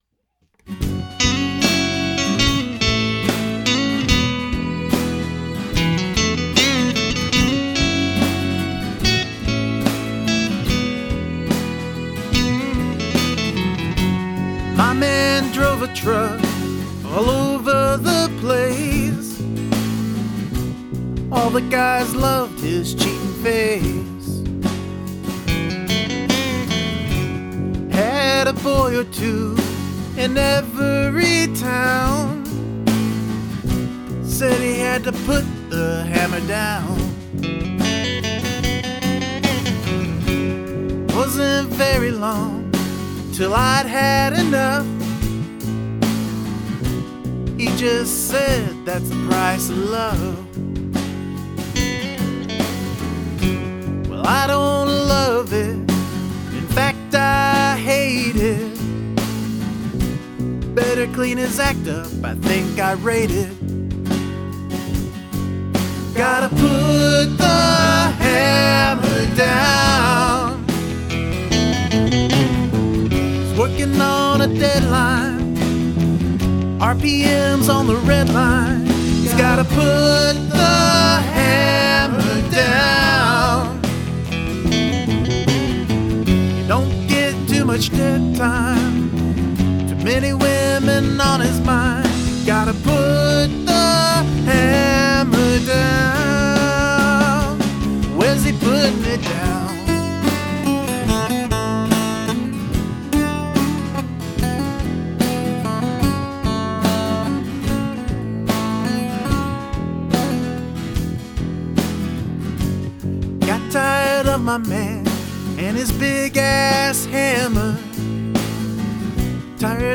Originally intended for a female vocal that never came through.
BR-900 into Reaper (drums added via EZDrummer)
The strings sound really sweet here!
The harmonies on the chorus are so lush, and the instrumentation is so nicely panned and balanced.
oh .... and I love the sound of the acoustic guitars !
Great vocal and harmonies.